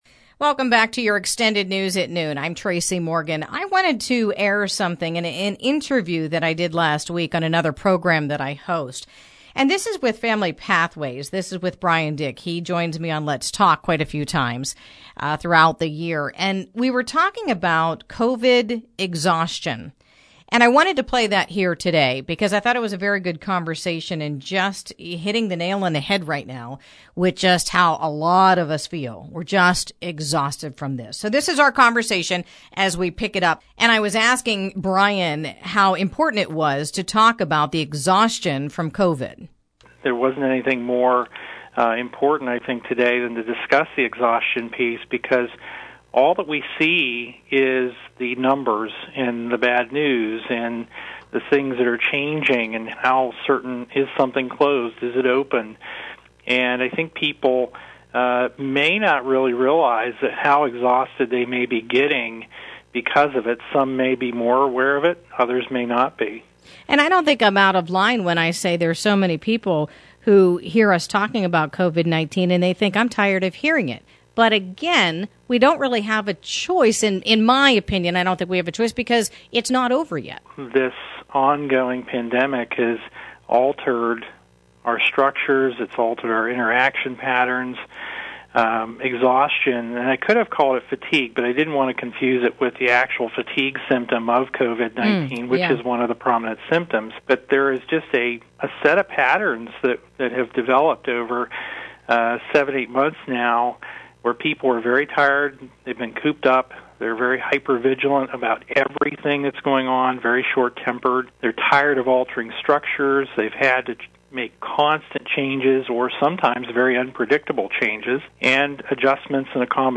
Extended Noon News Interview for Monday 11/16